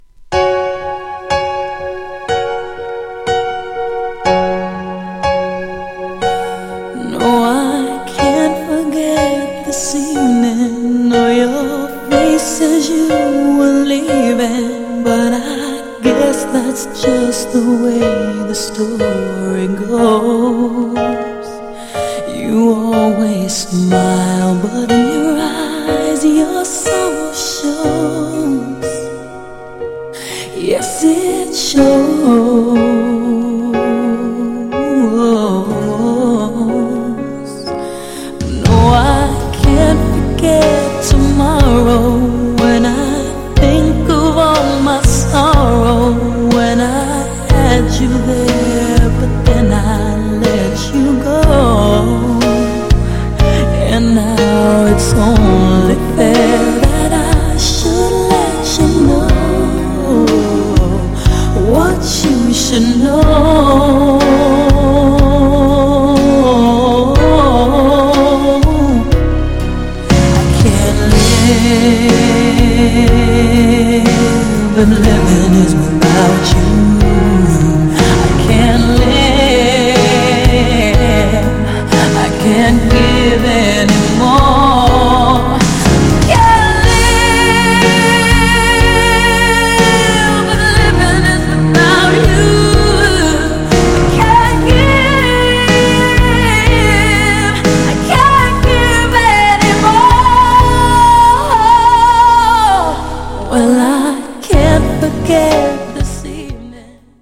GENRE R&B
BPM 61〜65BPM
# メロディアスR&B # 女性VOCAL_R&B # 定番のR&B # 歌心を感じる # 胸キュンナンバー